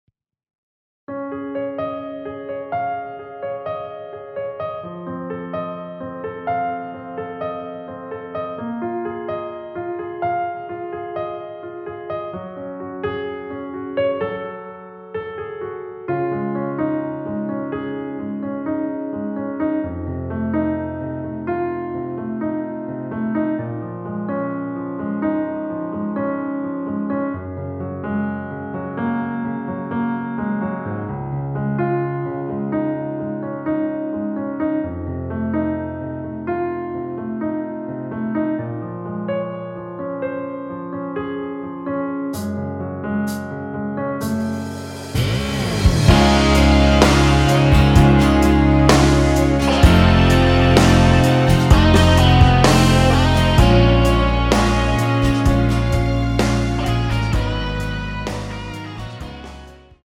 원키에서(-1)내린 (1절앞+후렴)으로 진행되게 편곡한 MR입니다.
Db
◈ 곡명 옆 (-1)은 반음 내림, (+1)은 반음 올림 입니다.
앞부분30초, 뒷부분30초씩 편집해서 올려 드리고 있습니다.